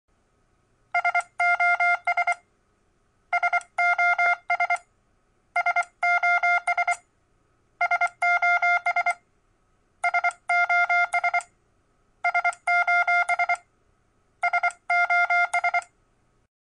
codigo_morsse_sos.mp3